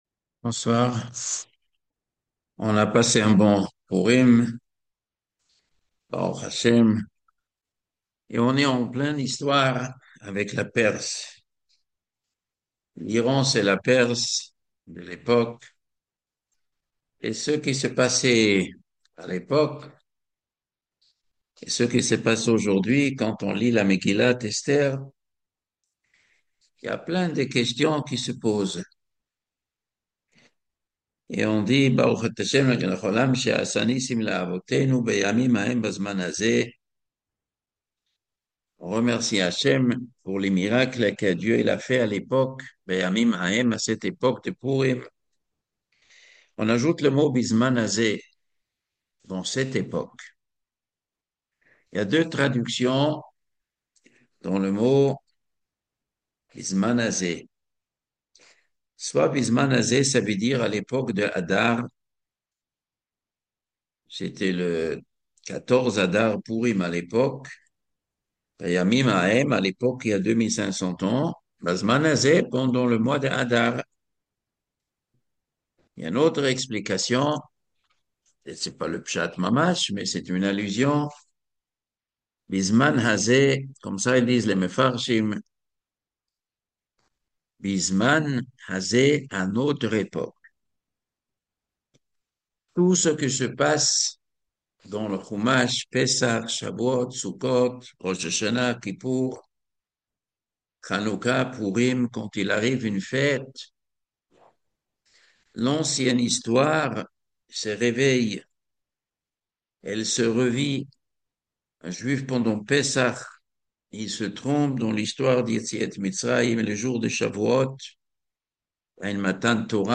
Conférence sur les évènements actuels – La perse et Israel